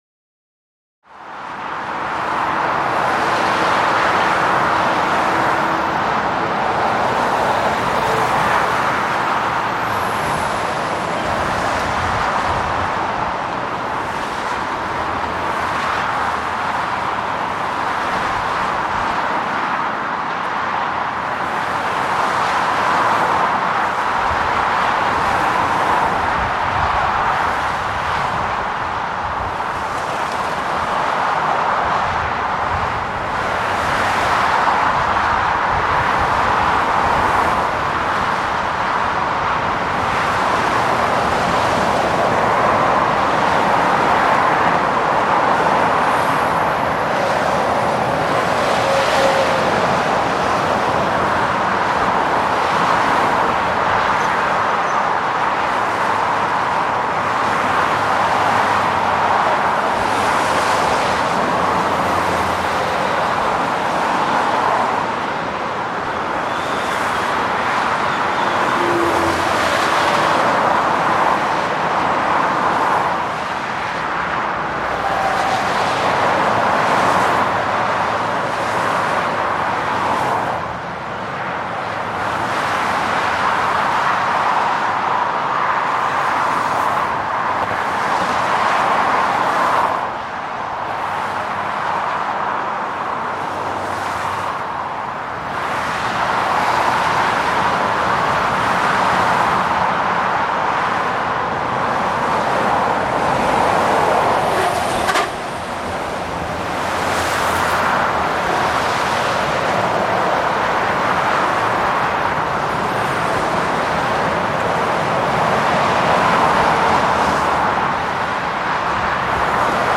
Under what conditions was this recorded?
On a bridge over the motorway